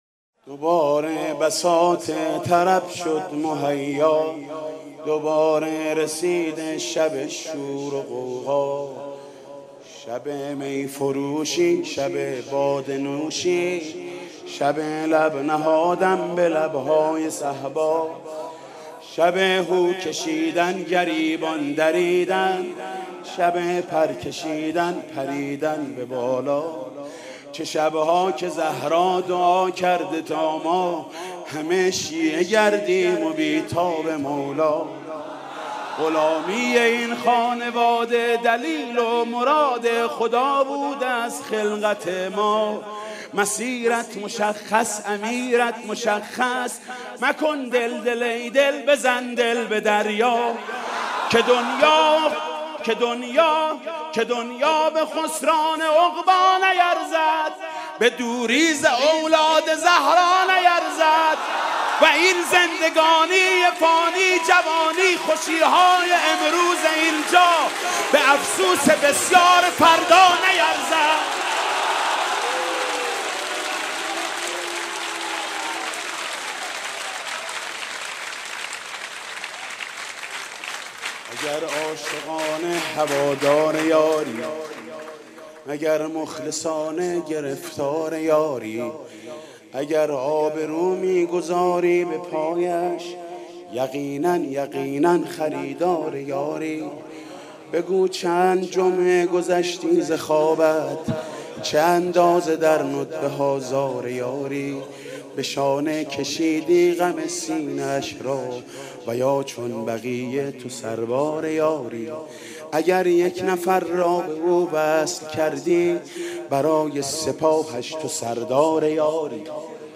مدح: به طاها به یاسین!